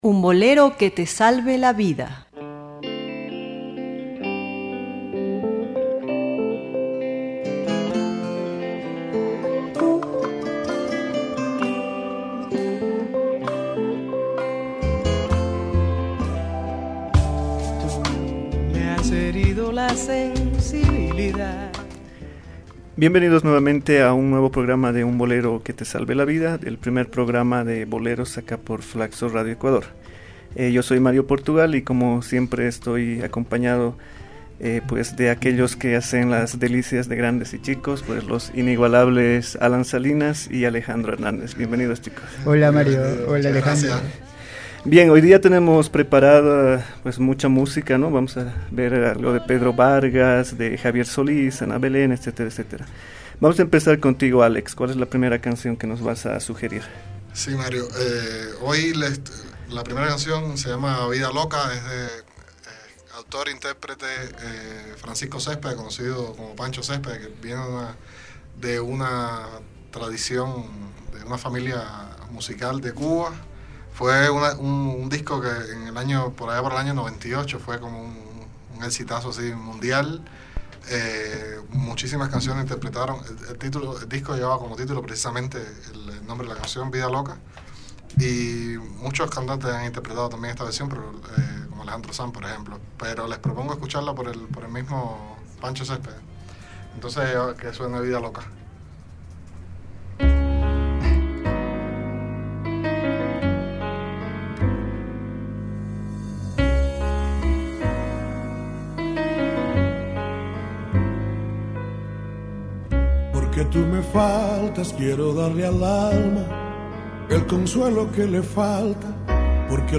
En nuestro quinto programa tenemos una selección de muy buenos boleros.